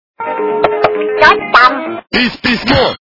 » Звуки » звуки для СМС » Кто там? - Пись-пись-мо...
При прослушивании Кто там? - Пись-пись-мо... качество понижено и присутствуют гудки.